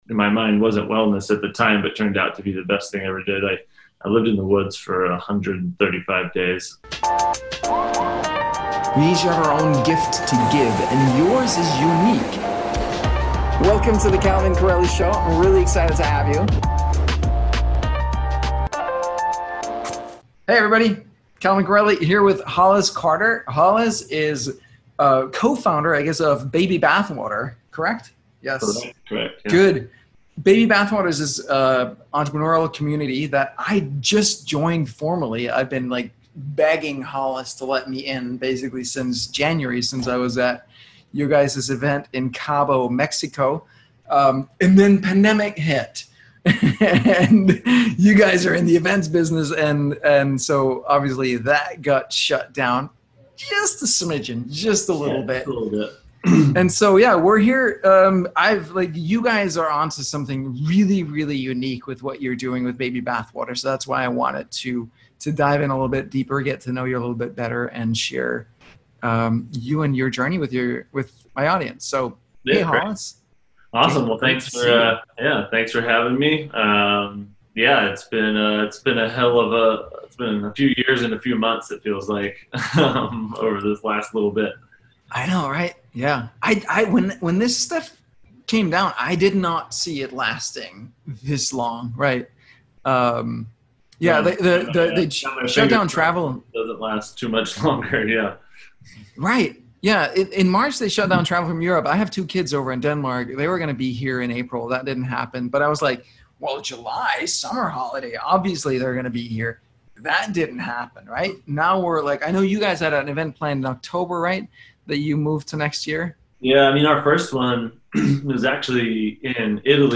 Networking, Entrepreneurship, Baby Bathwater An Interview